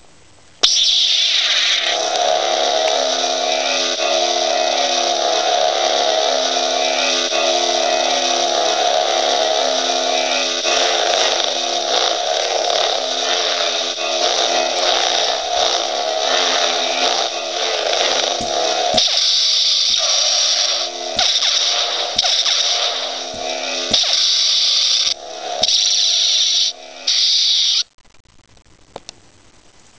アバウトに音種を解説：起動＝光刃が出る音。駆動＝ブ〜ンというハム音。移動＝振った時に鳴るヴォ〜ンという音。衝撃＝刃をぶつけた時に鳴るバシ〜ンという音。収縮＝光刃が消える音。